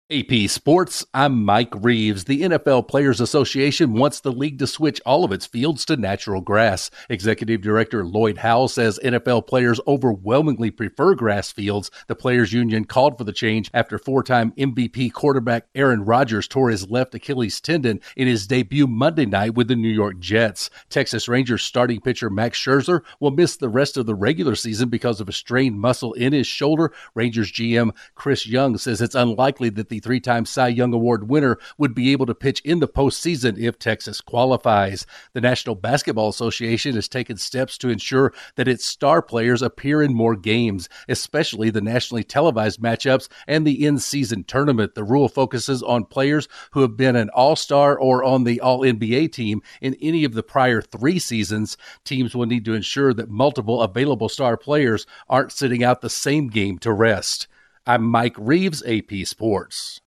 The NFL Players Association wants the league to implement safer playing surfaces, the Rangers will be without a key starting pitcher for the rest of the regular season, and the NBA wants its star players on the court more often. Correspondent